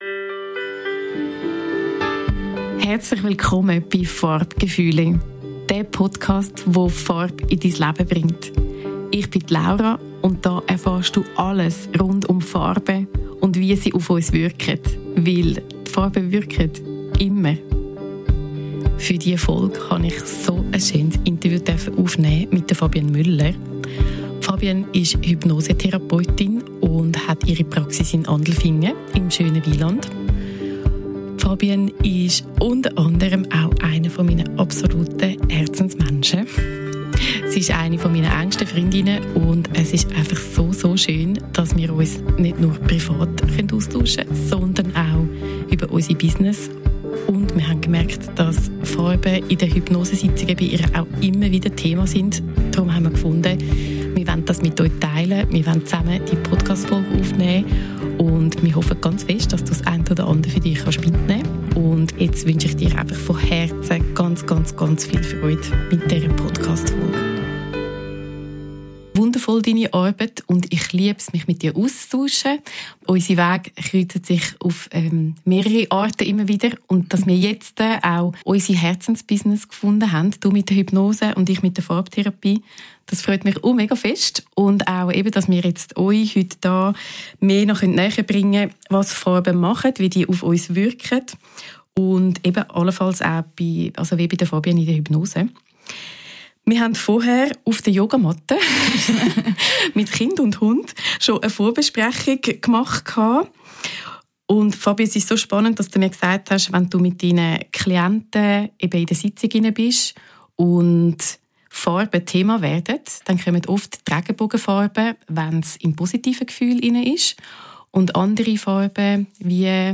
#7 Interview mit Hypnosetherapeutin